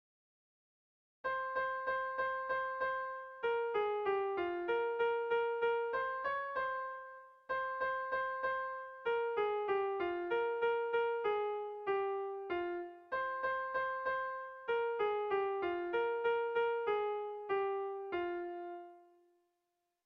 Erlijiozkoa
Seiko txikia (hg) / Hiru puntuko txikia (ip)
A1A2A2